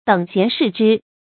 注音：ㄉㄥˇ ㄒㄧㄢˊ ㄕㄧˋ ㄓㄧ
等閑視之的讀法